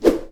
Swing Sword.wav